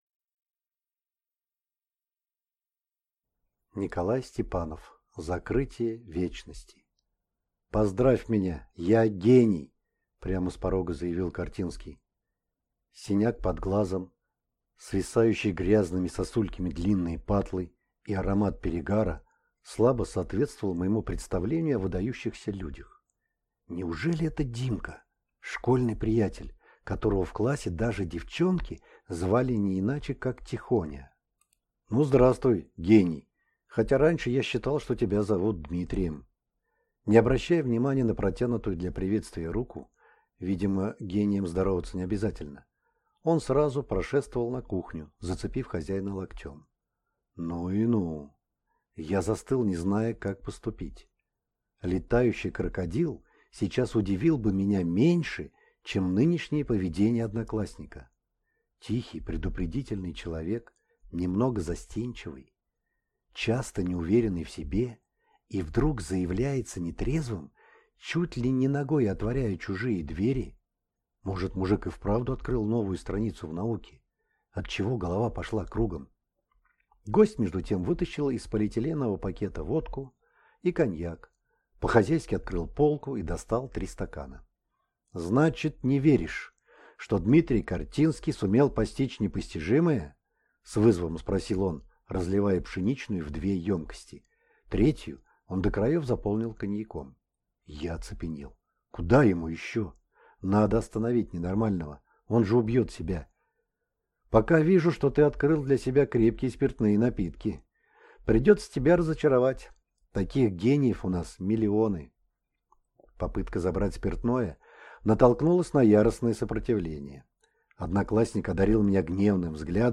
Аудиокнига Закрытие вечности | Библиотека аудиокниг